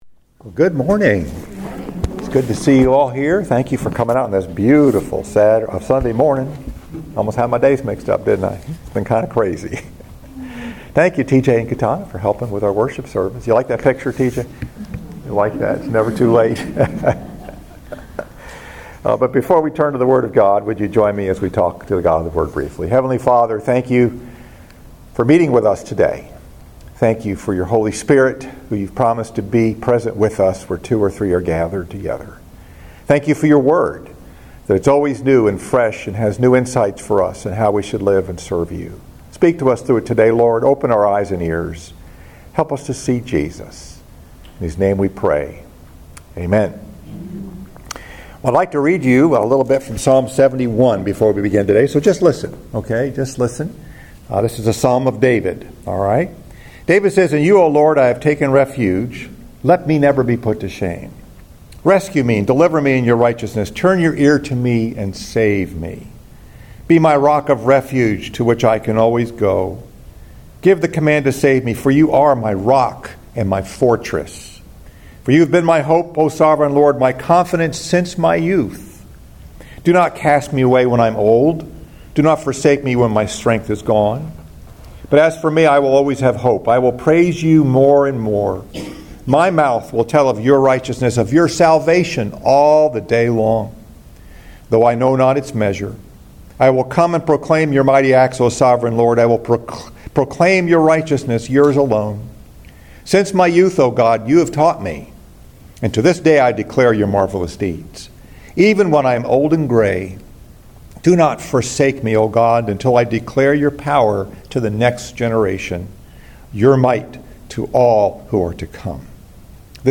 Message: “Never Too Late” Scripture: Psalm 71